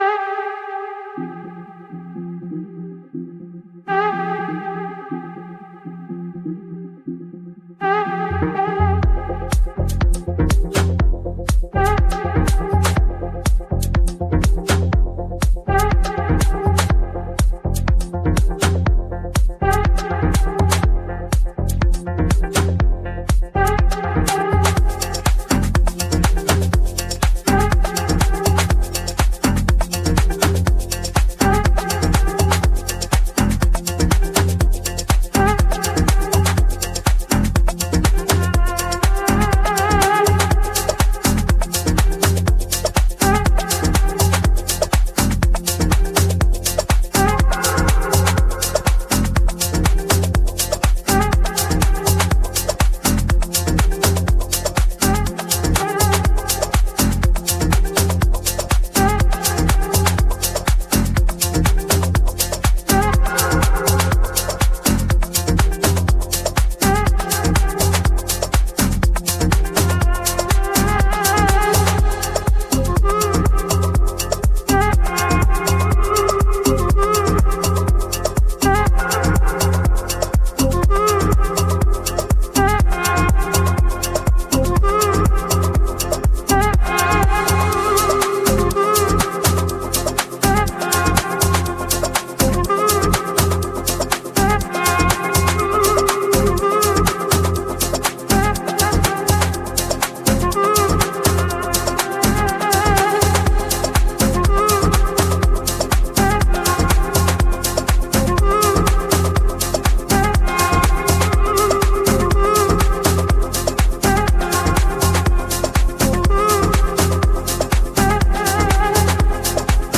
Genre: Progressive House